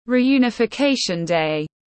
Ngày thống nhất đất nước tiếng anh gọi là Reunification Day, phiên âm tiếng anh đọc là /ˌriːˌjuːnɪfɪˈkeɪʃən deɪ/
Reunification Day /ˌriːˌjuːnɪfɪˈkeɪʃən deɪ/
Reunification-Day-.mp3